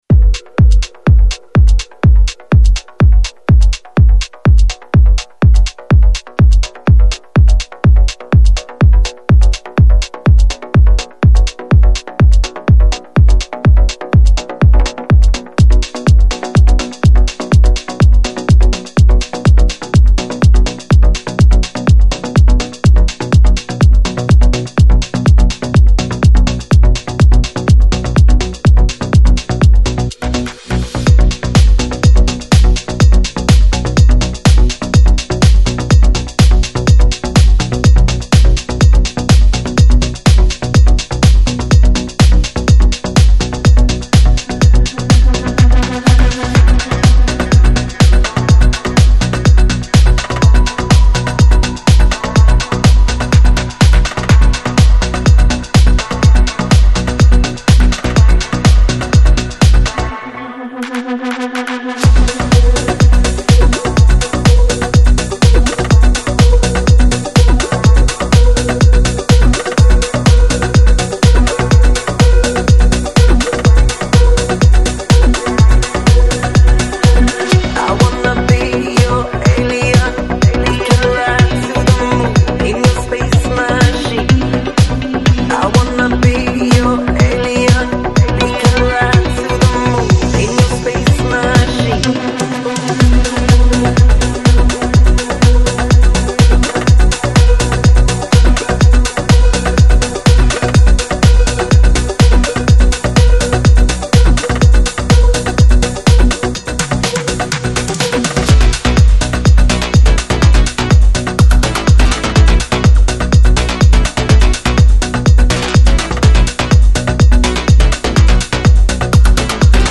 Жанр: Deep House, Tech House, Afro House